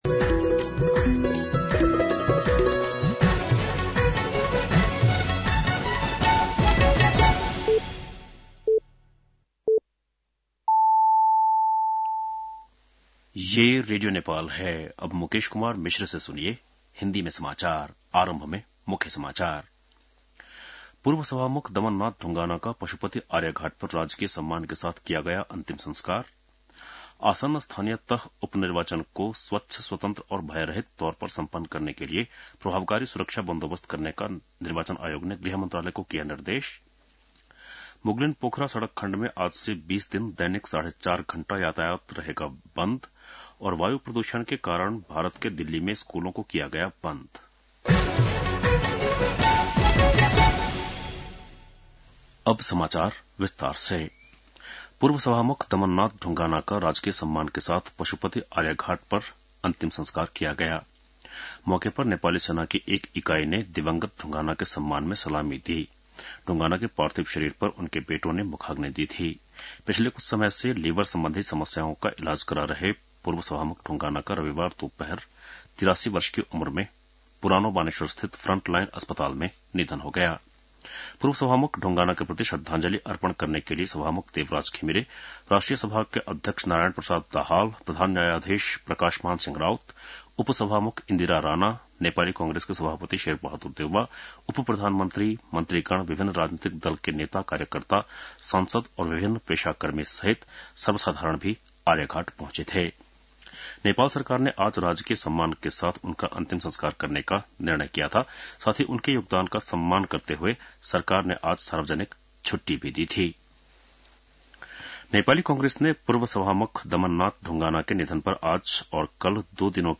बेलुकी १० बजेको हिन्दी समाचार : ४ मंसिर , २०८१
10-PM-Hindi-NEWS-8-03.mp3